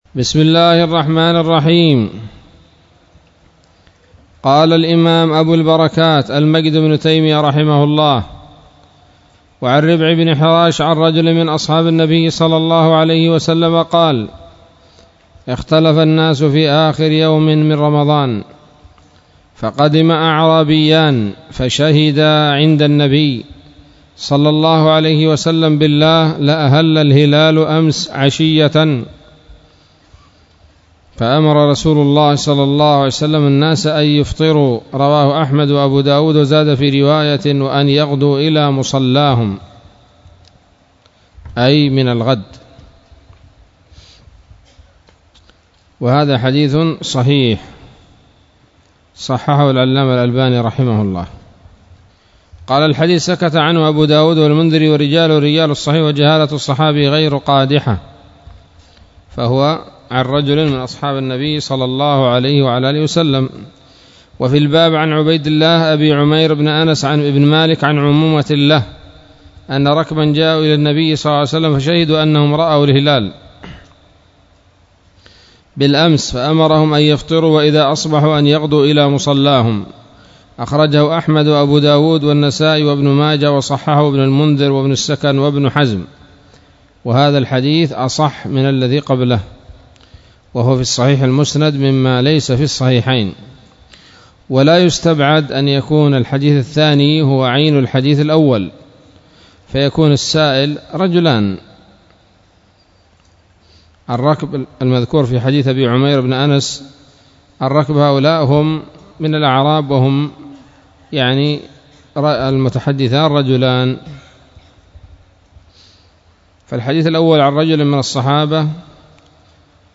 الدرس الثالث من كتاب الصيام من نيل الأوطار